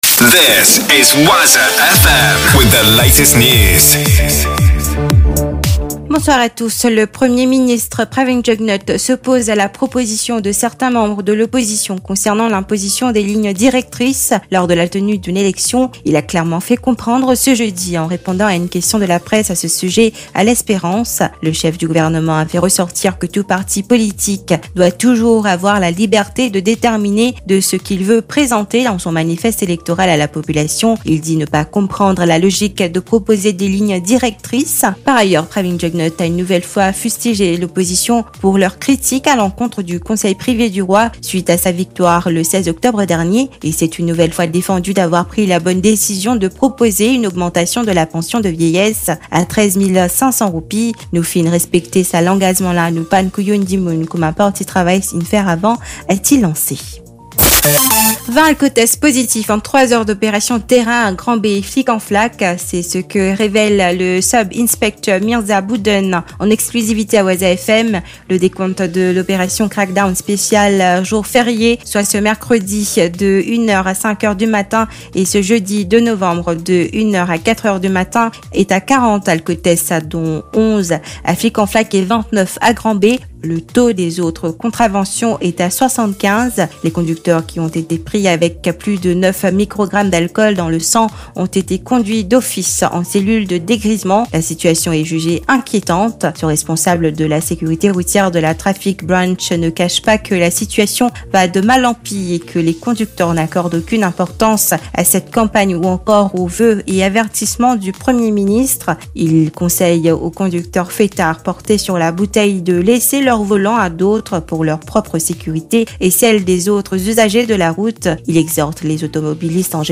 NEWS 17H - 2.11.23